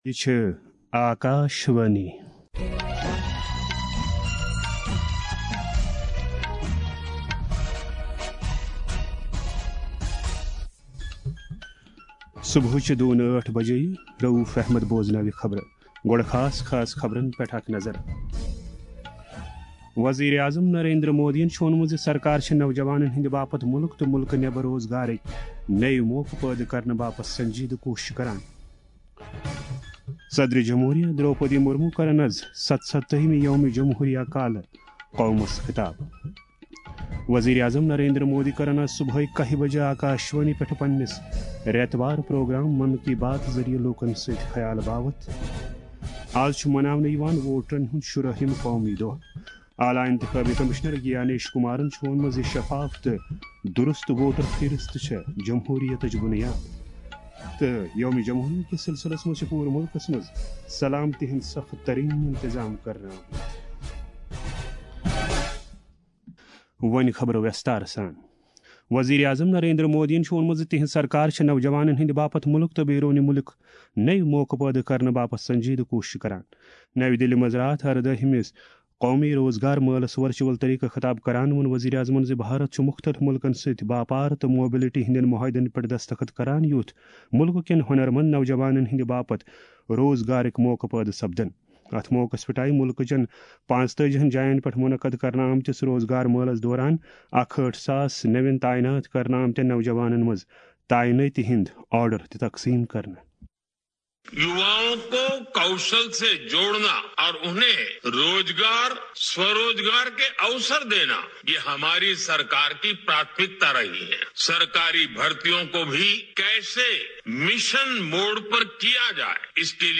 N-S-D-KASHMIRI-NEWS.mp3